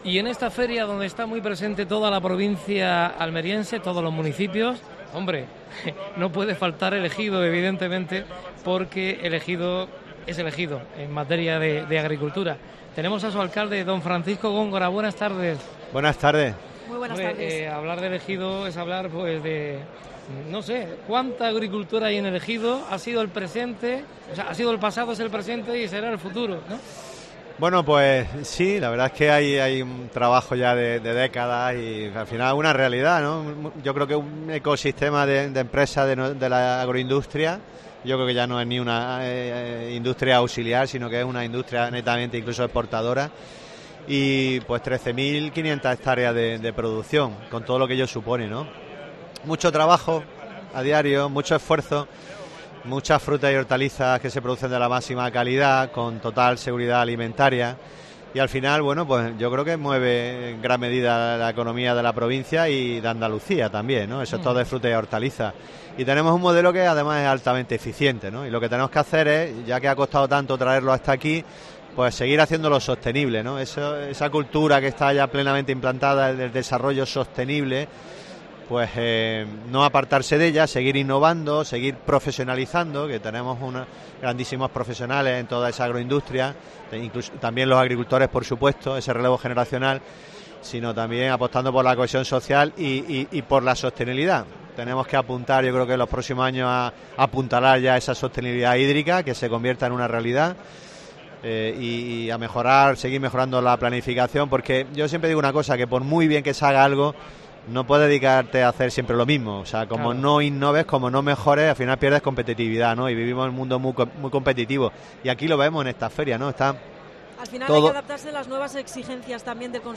Especial Fruit Attraction desde IFEMA (Madrid). Entrevista a Francisco Góngora (alcalde de El Ejido).